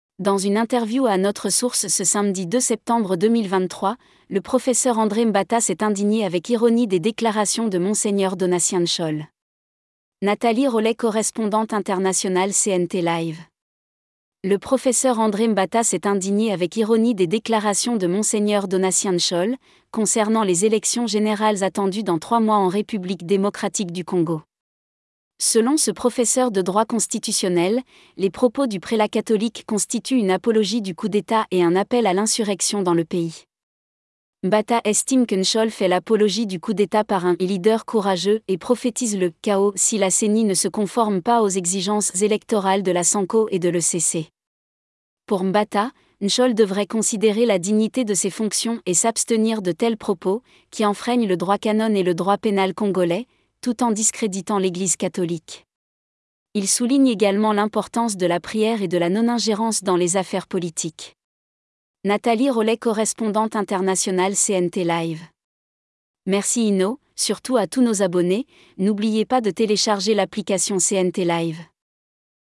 LISTEN TO THE NEWS HERE - ECOUTEZ Dans une interview à notre source ce samedi 2 septembre 2023, le professeur André Mbata s'est indigné avec ironie des déclarations de Monseigneur Donatien NSHOLE.